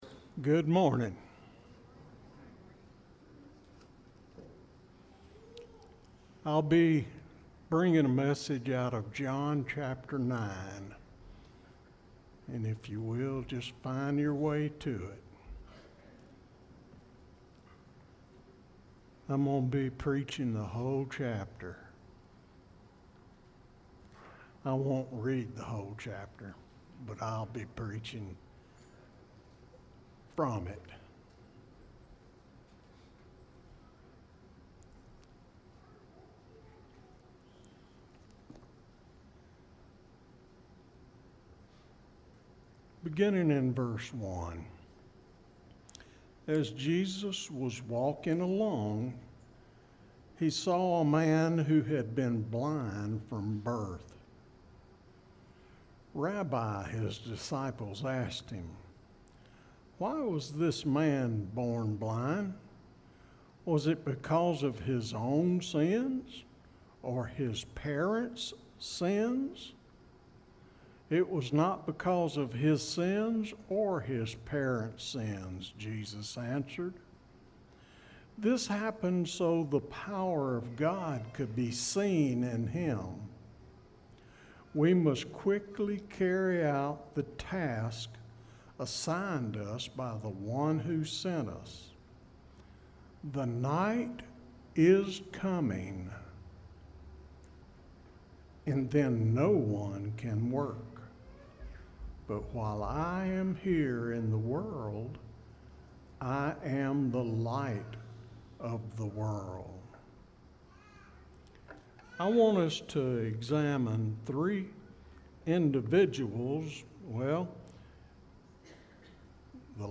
Aug-2-sermon-audio.mp3